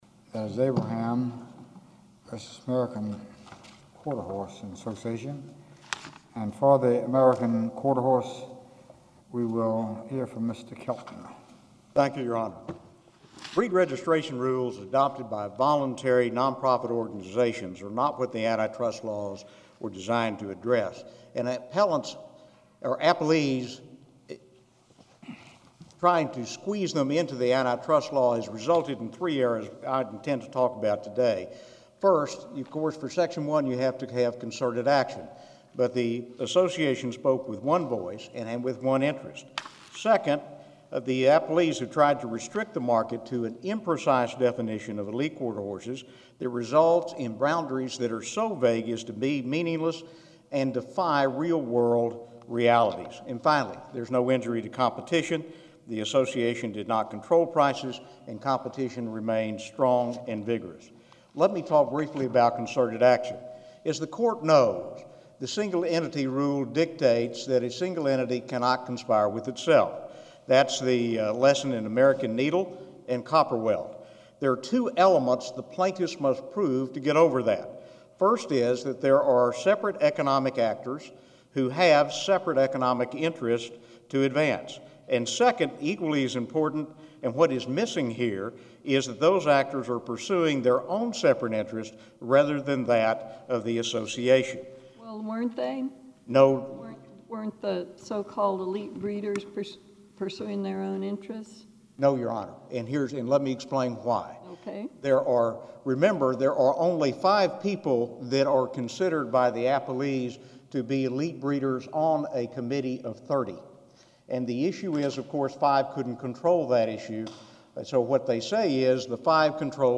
± Oral argument before the Seventh Circuit (June 2, 2008)